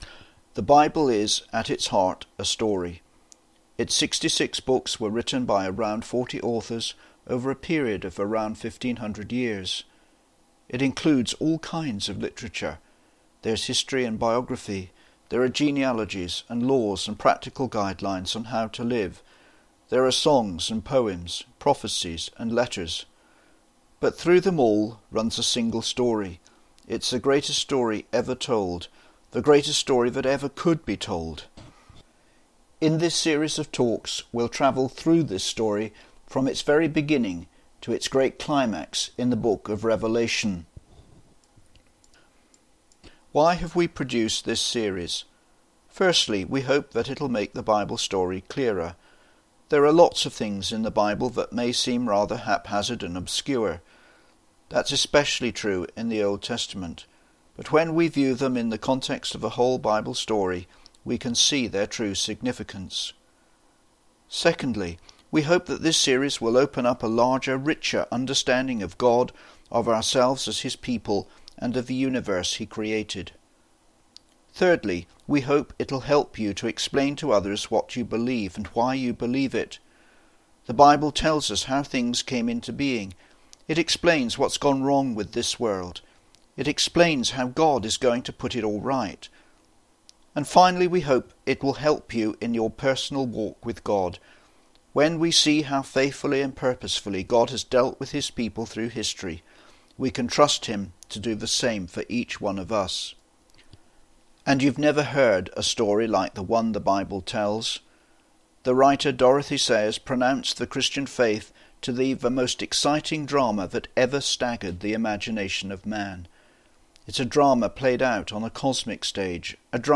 We are beginning to upload The Big Journey as a series of audio talks. The first one, entitled Charting Our Course is available here as an MP3 – just click on the MP3 icon below: